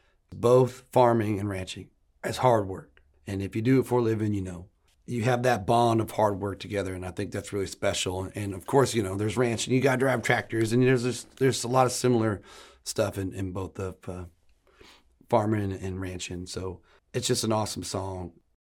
Jon Pardi talks about the similarities between Cowboys and Plowboys.